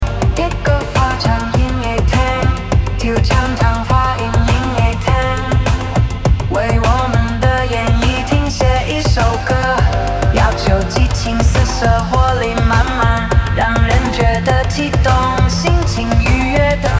要求激情四射活力满满！